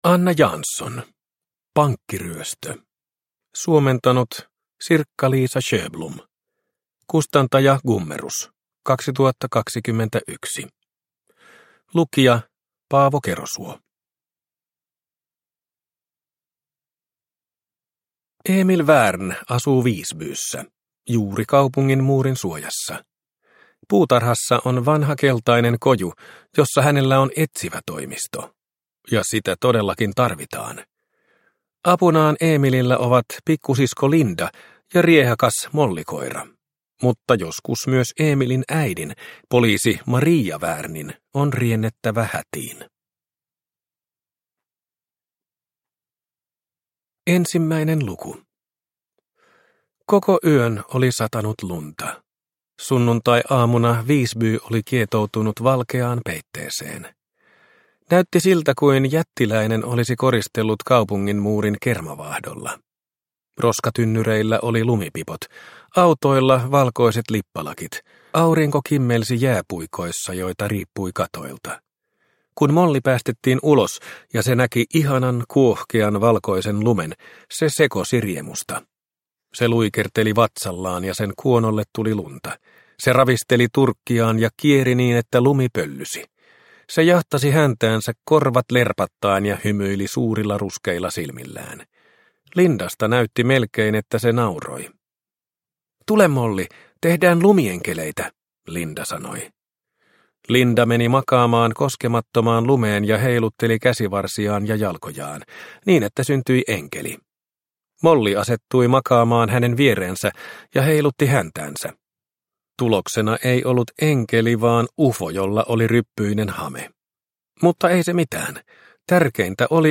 Pankkiryöstö – Ljudbok – Laddas ner